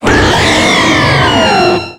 Cri d'Yveltal dans Pokémon X et Y.